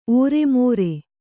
♪ ōremōre